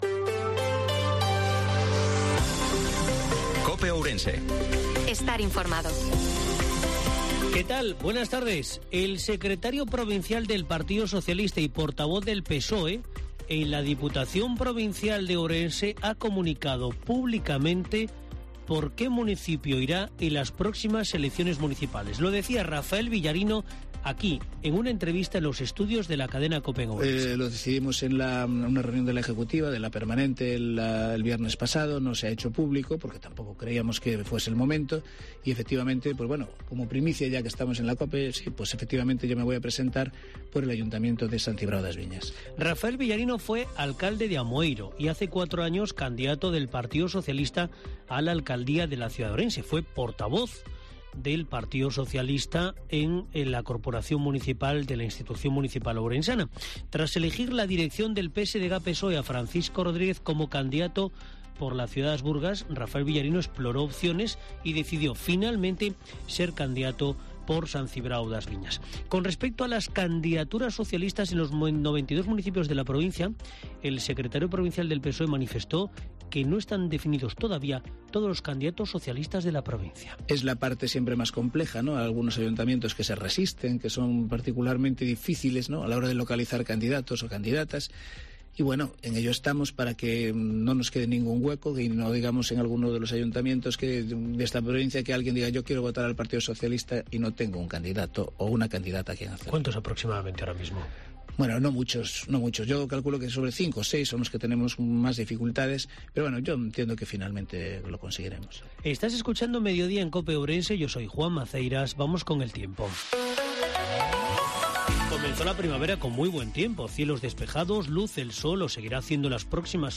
INFORMATIVO MEDIODIA COPE OURENSE-21/03/2023